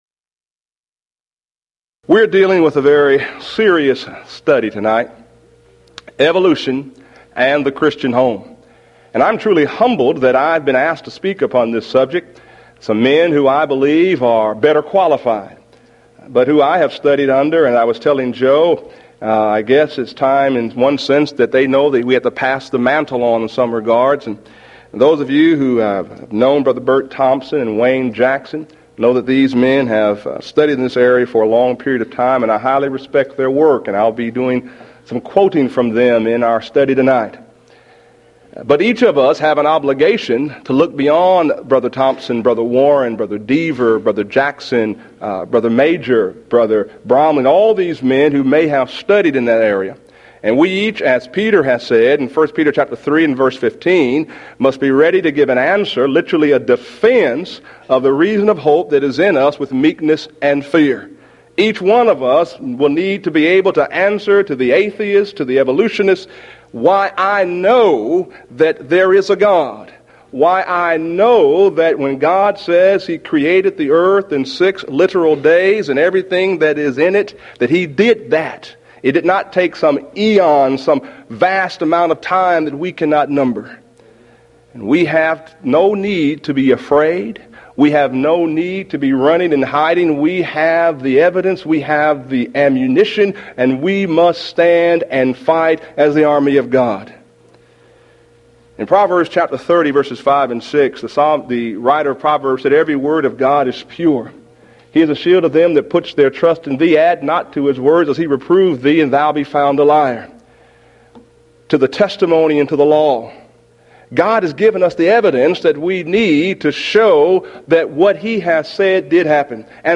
Event: 1993 Mid-West Lectures
lecture